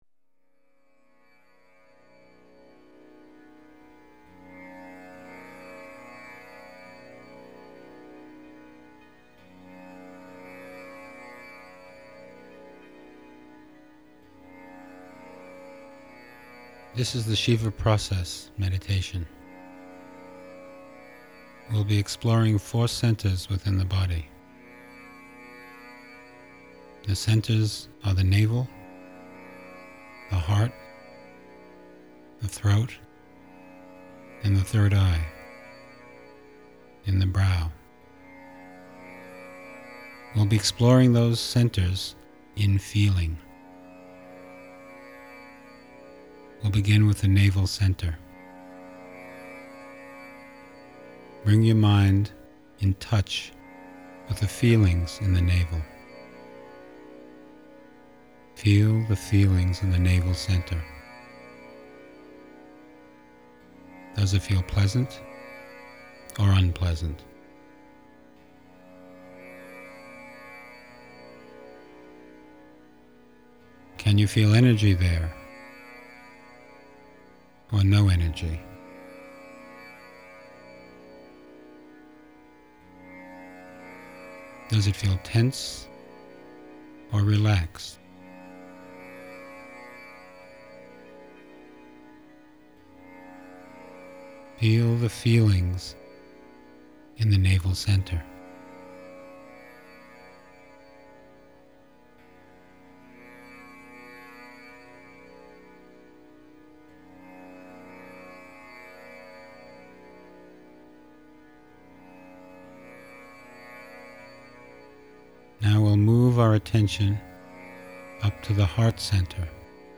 In this meditation you investigate the thoughts and feelings in four of the seven chakras: the navel, the heart, the throat and the brow. I ask questions like: “Is the feeling pleasant or unpleasant, tense or relaxed?”
Follow my narration using its statements and images to release the tension.
01-chakra-meditation.m4a